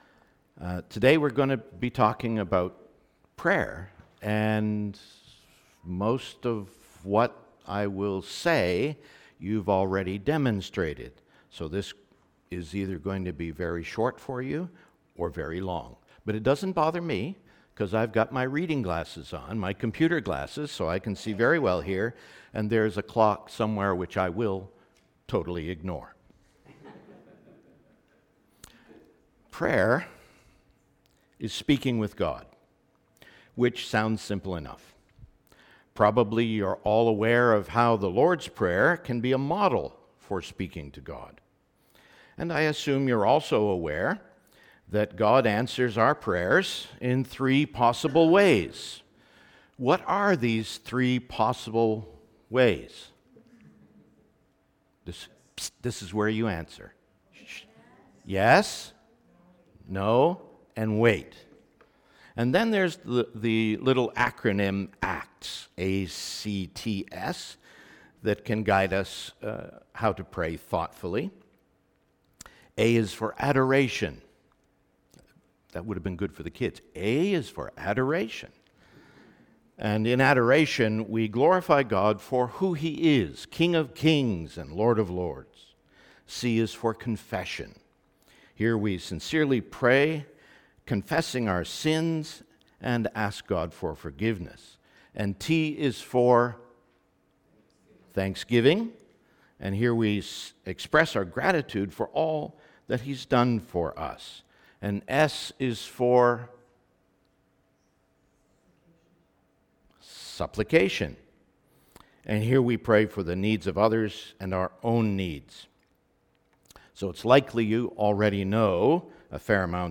Zephaniah 2:4-3:10 Service Type: Sermon